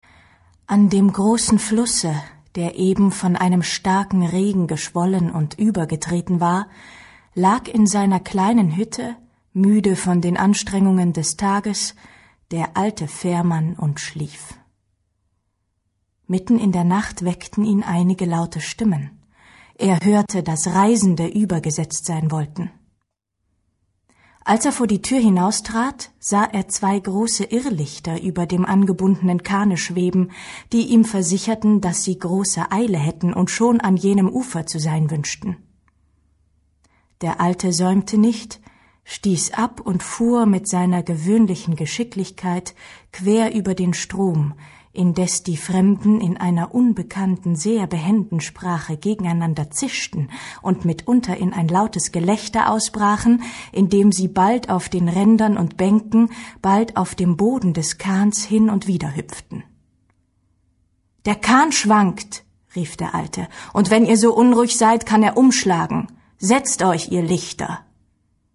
Johann Wolfgang von Goethe Sprecherin